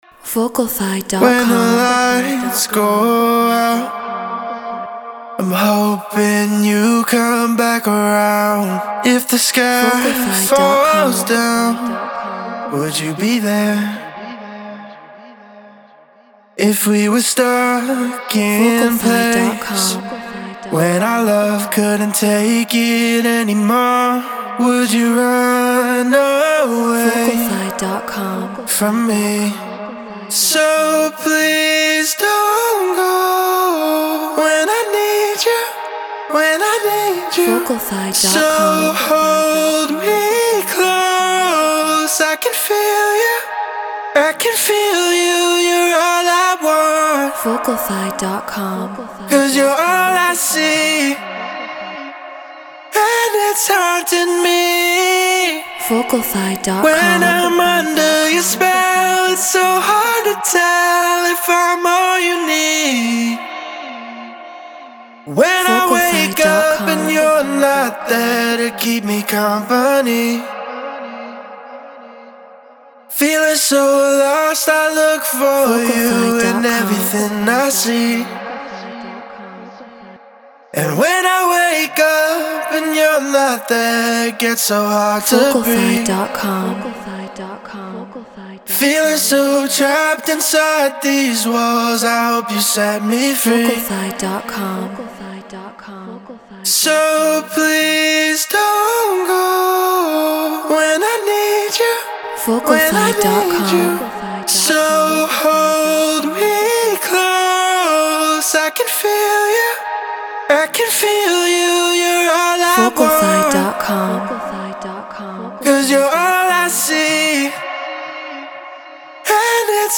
House 123 BPM D#min
Treated Room